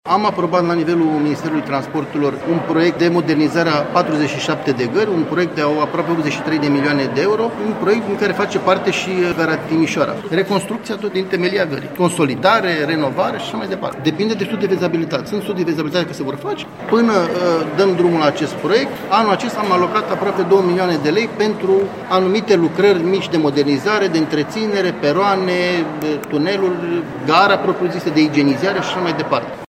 Gara de Nord din Timișoara va fi modernizată cu bani europeni. Secretarul de stat în Ministerul Transporturilor, Dragoș Titea, a declarat, la Timișoara, că, în total, a fost obținută o finanţare de aproape 83 de milioane de euro, pentru refacerea a 47 de gări. Până la demararea lucrărilor, Timișoara primește două milioane de lei pentru reparații. dragos titea gara